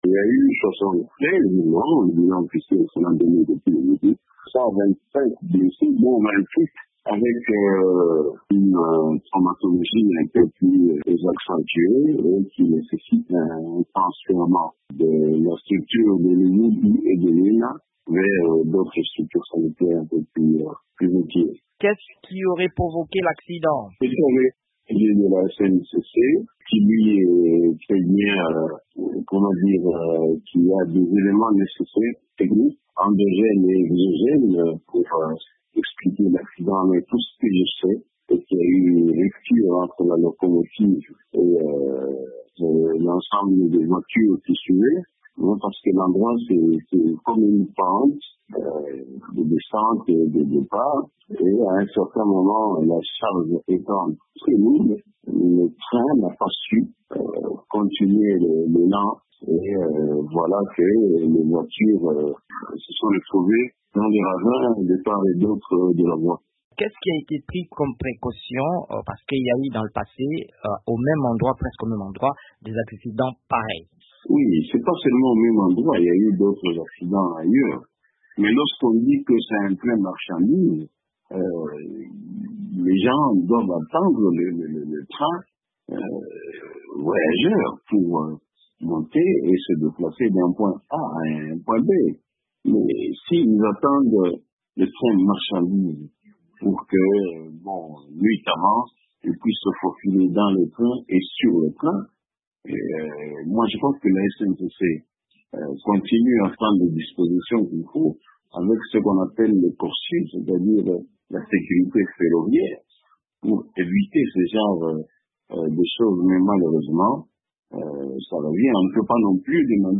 En République démocratique du Congo, au moins soixante-quinze personnes ont perdu la vie le week-end dernier dans le déraillement d’un train à Buyofwe, dans la province du Lualaba, dans le sud-est. Il s’agit d’un énième accident du genre qui laisse plus de 125 blessés dont certains avec des traumatismes graves. Le ministre de l’Intérieur de la province, Deodat Kapenda, réagit depuis Kolwezi.